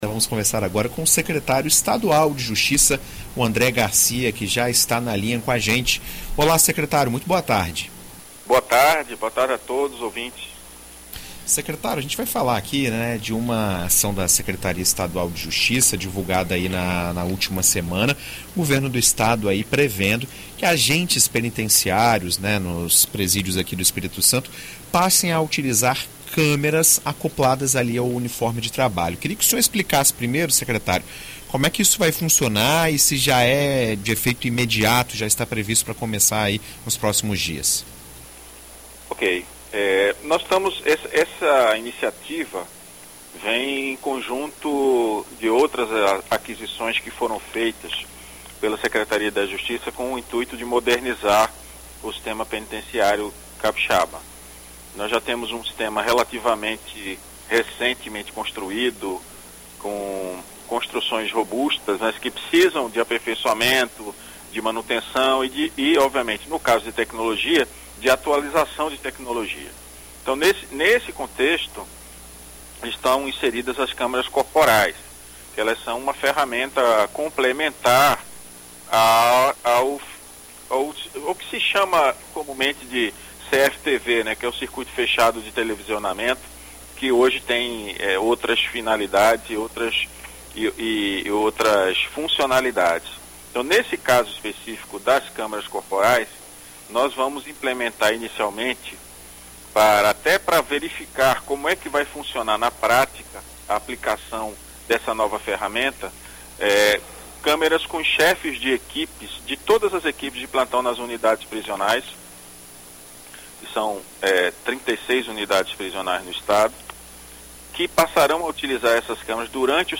Em entrevista à BandNews FM ES nesta segunda-feira (13), o Secretário de Justiça, André Garcia, fala sobre a legitimação das condutas ligadas aos diretos e deveres dos servidores e detentos.